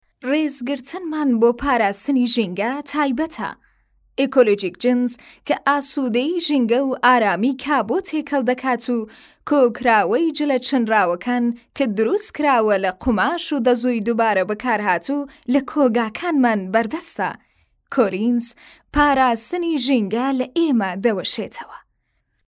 Narration
Female
Young